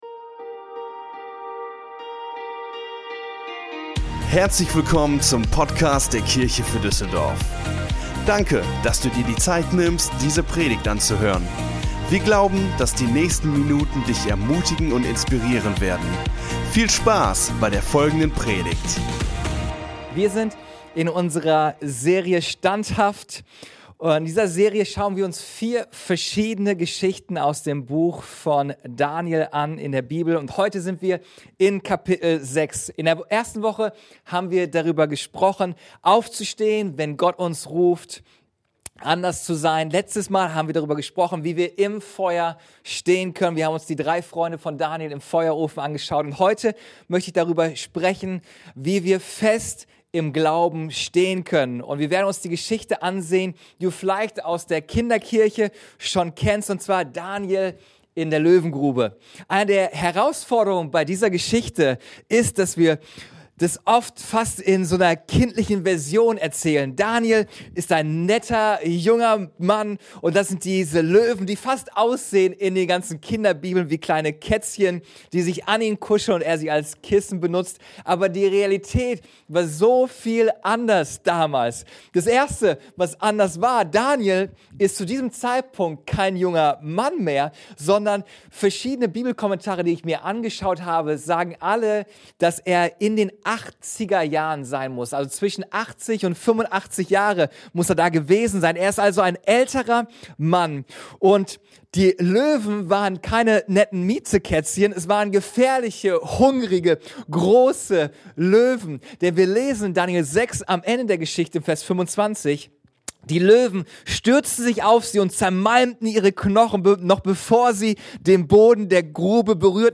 Der dritte Teil unserer Predigtserie: "Standhaft" Folge direkt herunterladen